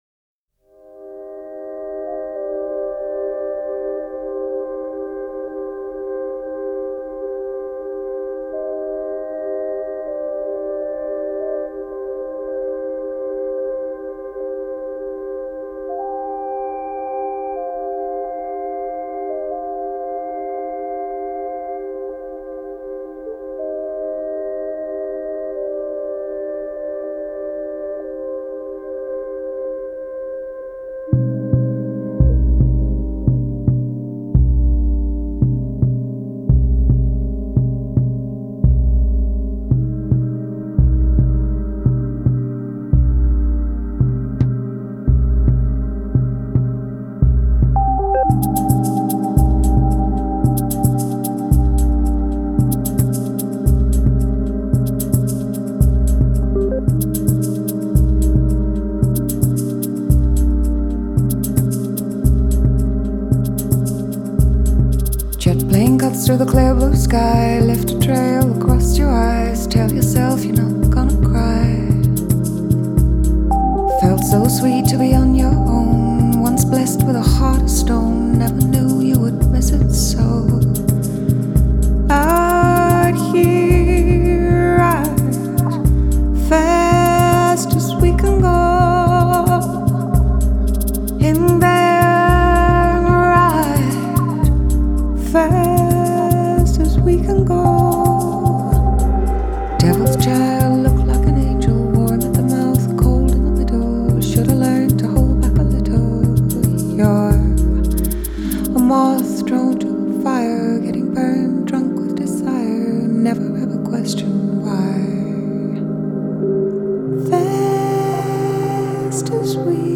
Жанр: Jazz.